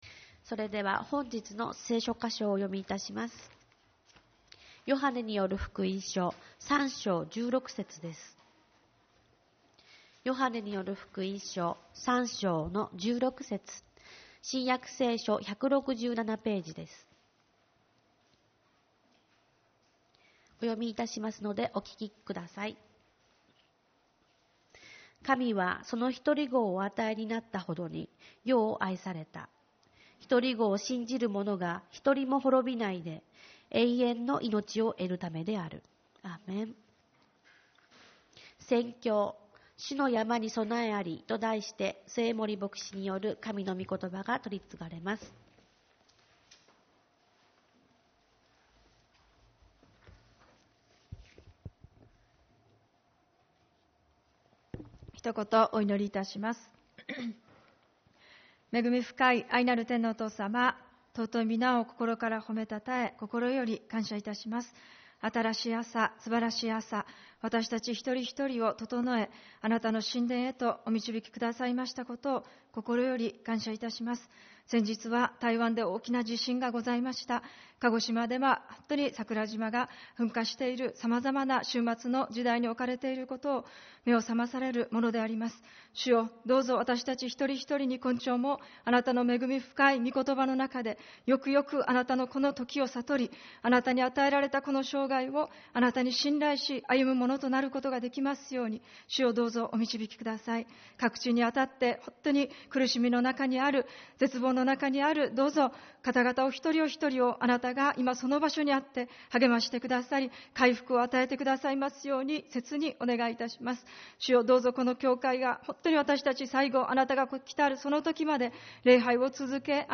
主日礼拝 「主の山に備えあり｣